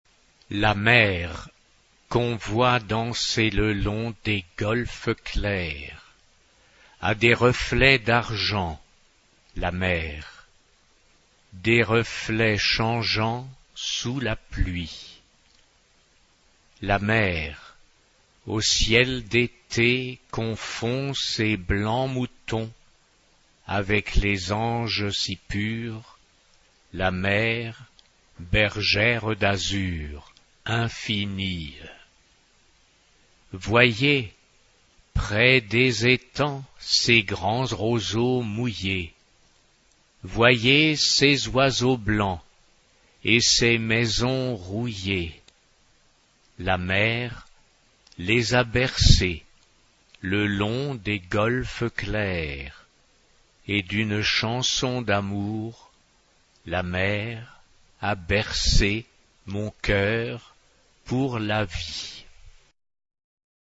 SATB (4 voces Coro mixto) ; Partitura general.
Coral jazz.
Carácter de la pieza : swing
Instrumentos: Piano (1)
Tonalidad : fa mayor